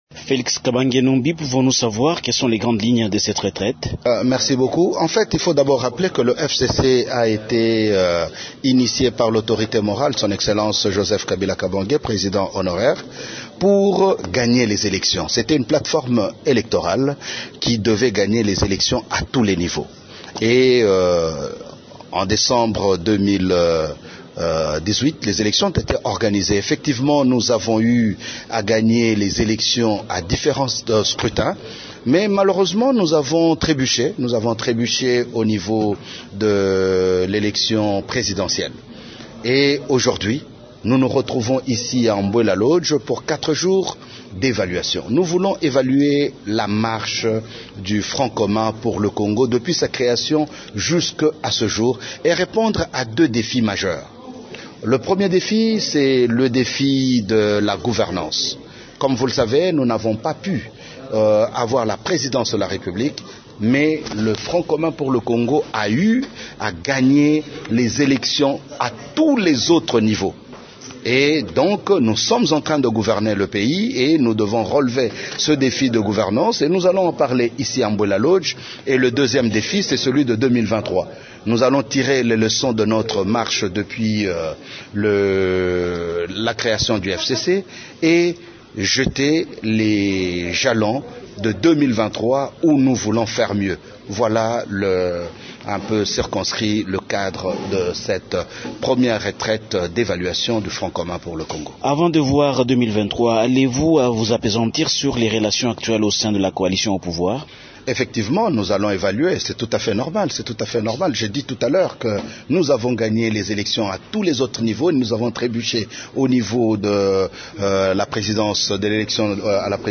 Félix Kabange, membre du comité organisateur de cette retraite du FCC s’entretient